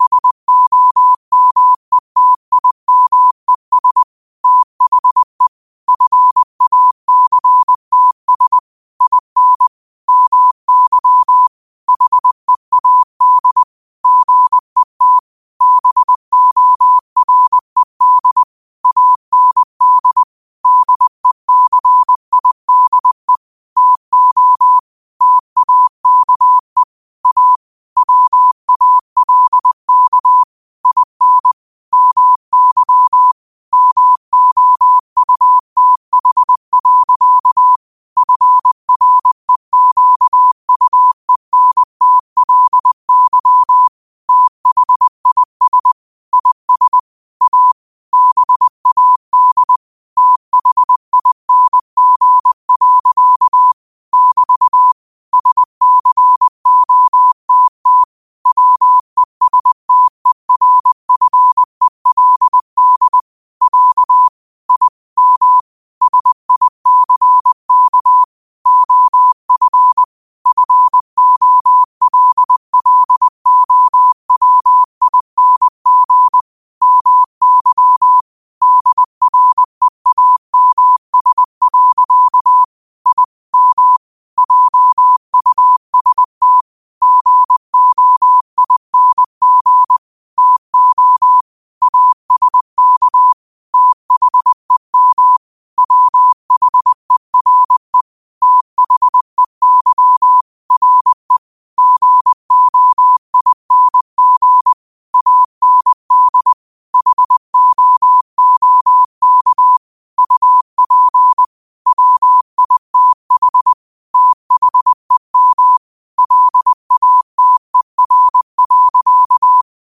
New quotes every day in morse code at 20 Words per minute.